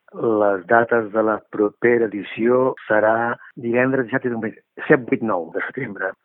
El regidor de turisme també ha explicat que les dates per la propera edició ja estan tancades.